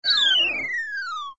ENC_Lose_shrinking.ogg